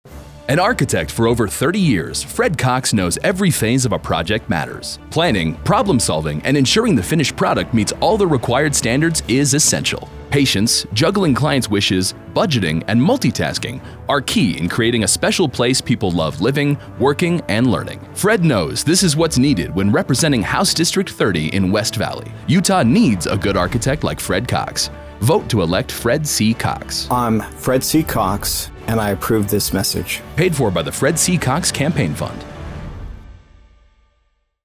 Past Radio Advertisement